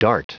Prononciation du mot dart en anglais (fichier audio)